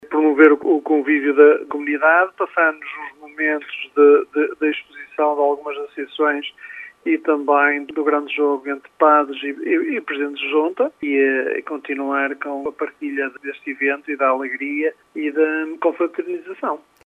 O Dia da Freguesia de Lijó celebra-se no feriado 10 de junho com um programa recreativo e desportivo no Complexo Desportivo de Lijó. Filipe Oliveira é presidente da Junta de Freguesia e traça os objetivos do evento